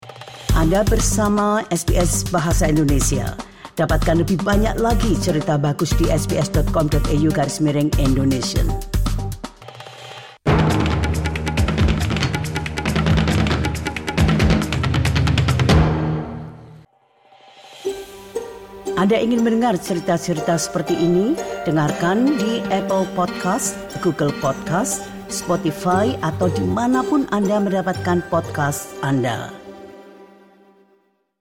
Berita terkini SBS Audio Program Bahasa Indonesia – 18 Agustus 2025